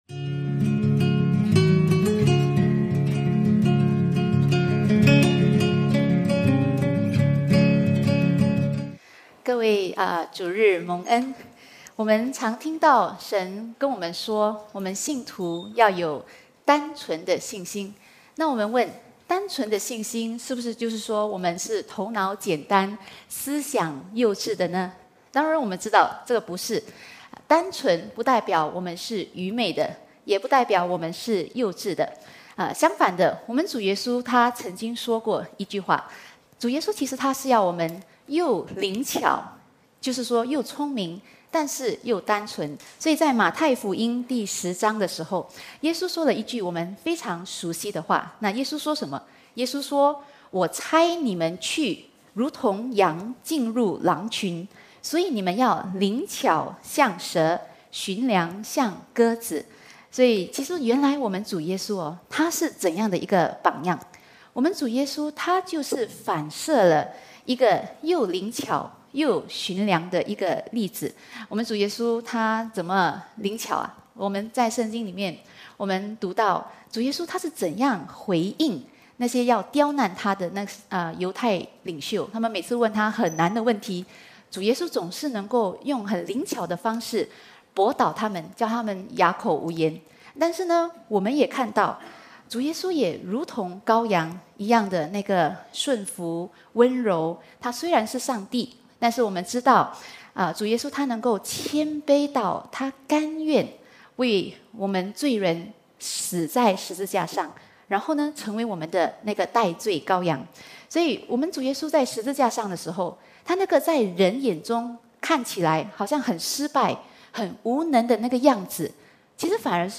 ad-preach-单纯却不幼稚的信心.mp3